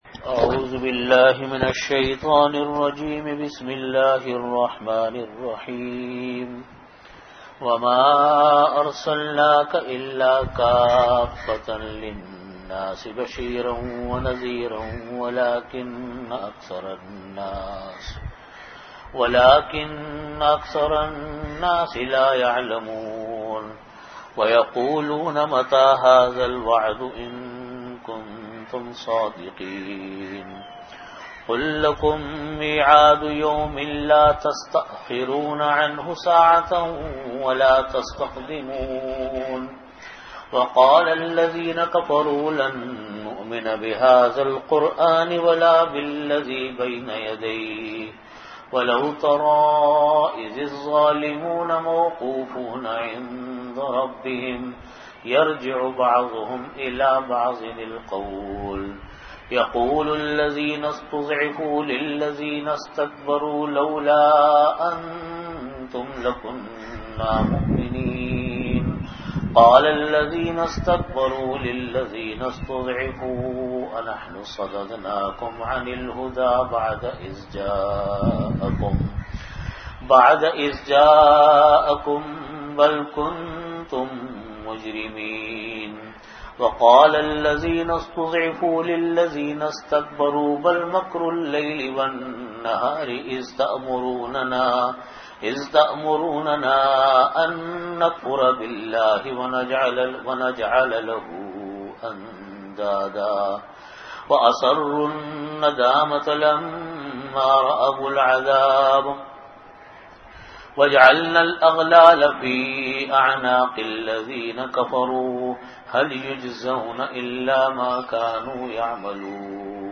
Tafseer
Time: After Asar Prayer Venue: Jamia Masjid Bait-ul-Mukkaram, Karachi